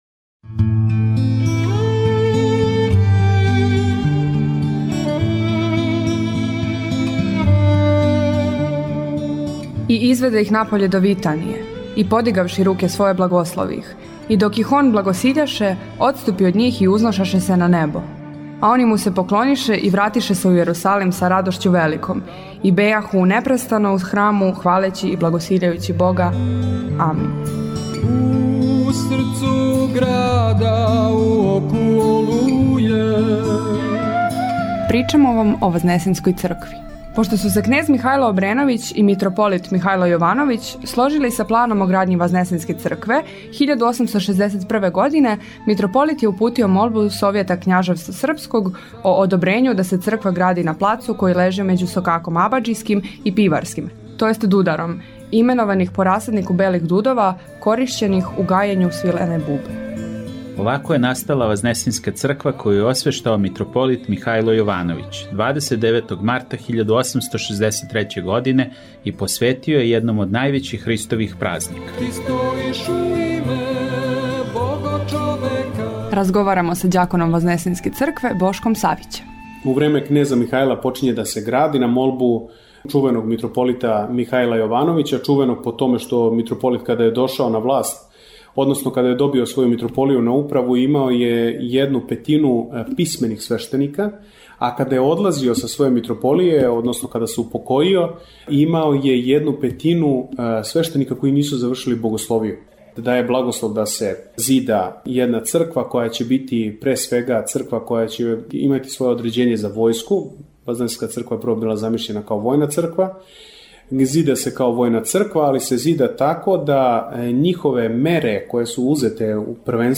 Поводом великог празника Вазнесења Госпoдњег – славе Храма и свих Београђана, Радио Слово љубве дарује слушаоцима кратку репортажу која осликава живот Вазнесењске цркве у центру Београда као живе Цркве Христове.